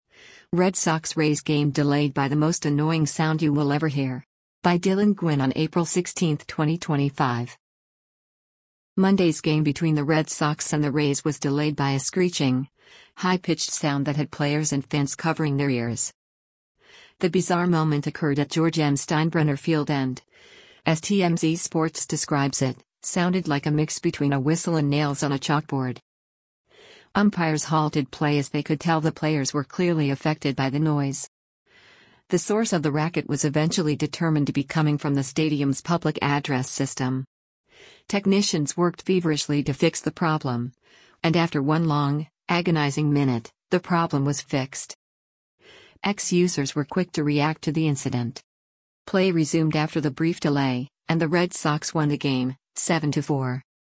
Monday’s game between the Red Sox and the Rays was delayed by a screeching, high-pitched sound that had players and fans covering their ears.
The bizarre moment occurred at George M. Steinbrenner Field and, as TMZ Sports describes it, “sounded like a mix between a whistle and nails on a chalkboard.”
The source of the racket was eventually determined to be coming from the stadium’s public address system.